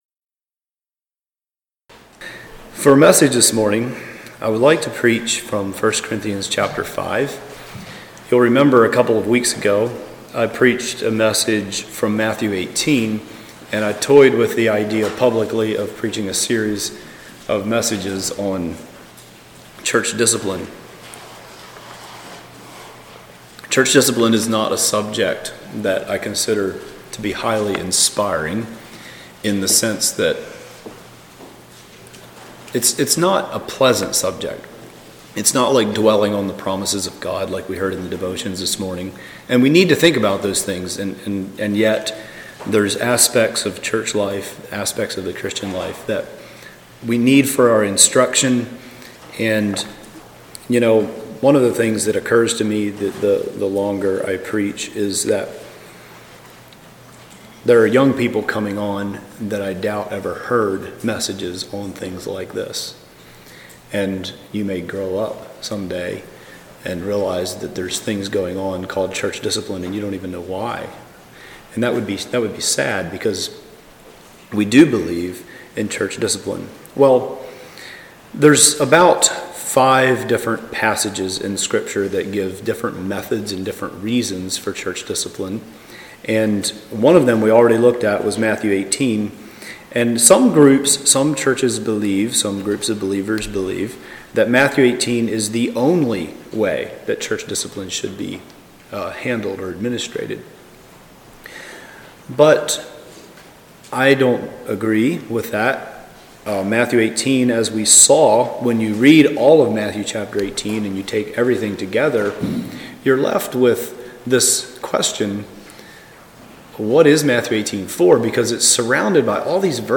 This two-part sermon discusses five Scripture passages related to this subject. Discipline is not done TO, but FOR, someone.